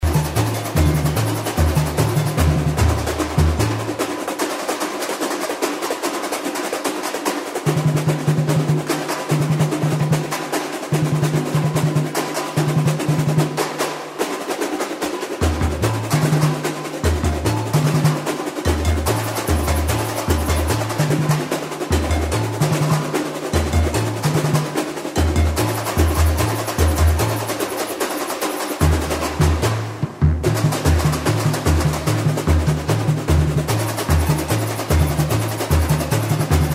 Breaks
puro-balanco-tamb.mp3